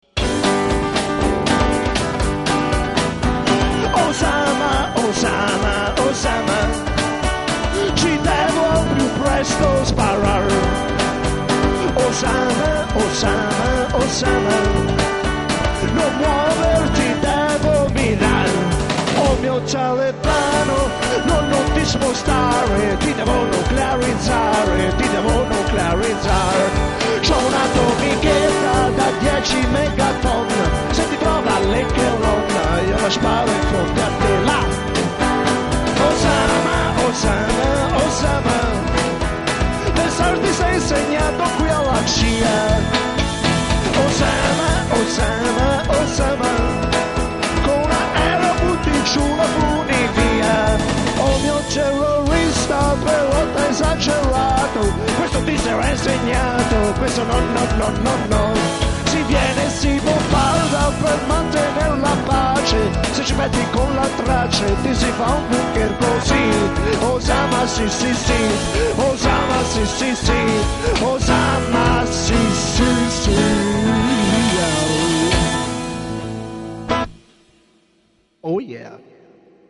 Gliela spiegavo durante il pezzo, apprezzate la forza della spontaneita'!